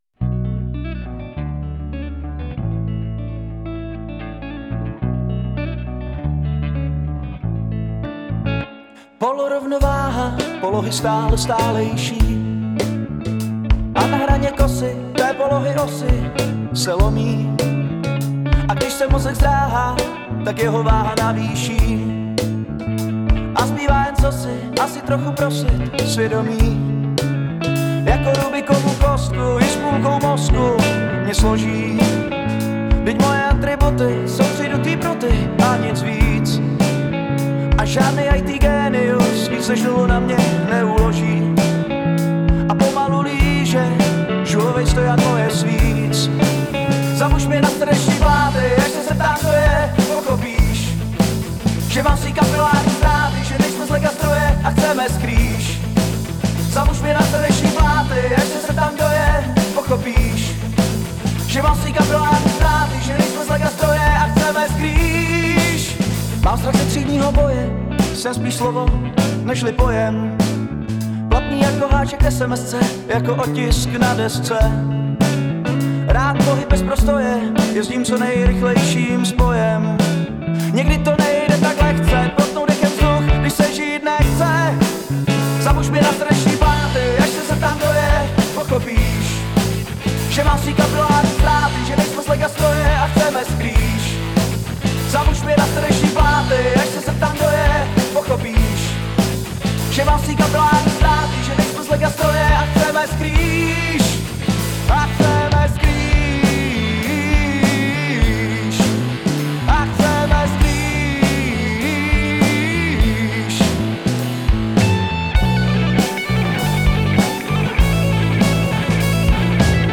Jedna JB s roundwoundy, jedna PB s Thomastiky.